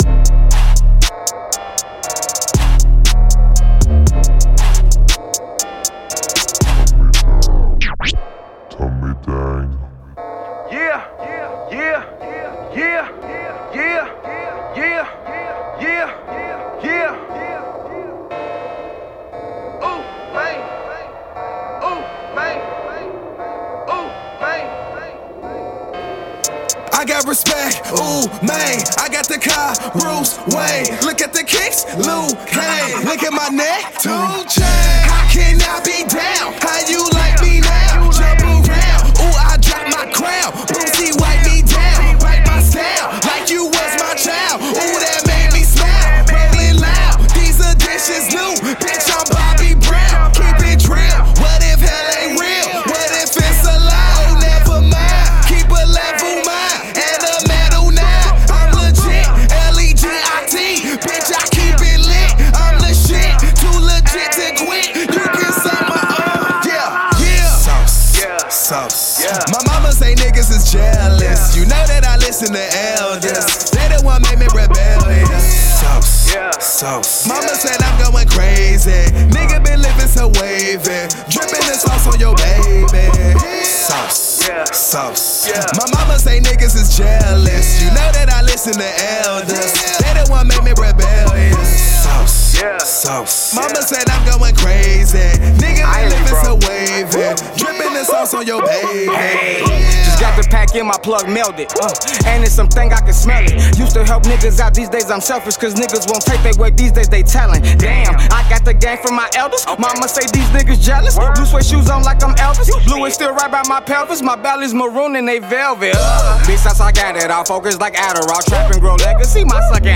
hip hop / rap